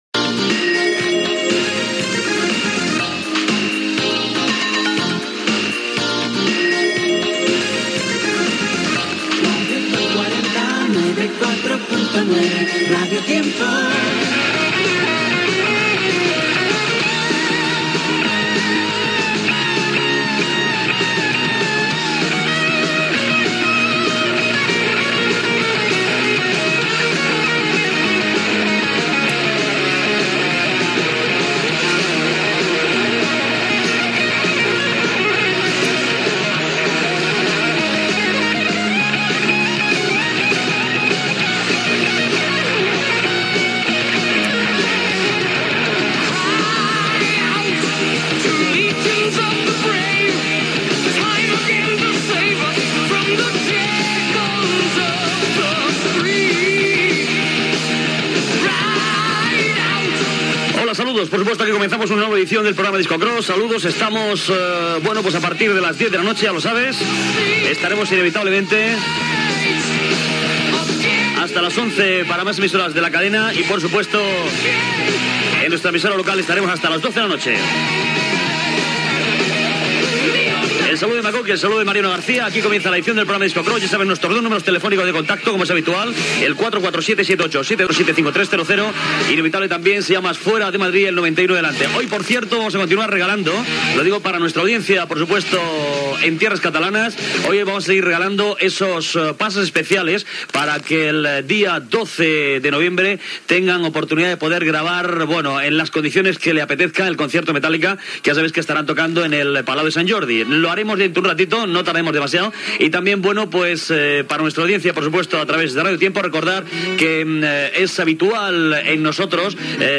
Indicatiu de la ràdio, sintonia, presentació del programa, telèfon de contacte, missatge a l'audiència catalana de Radio Tiempo, tema musical
Musical
FM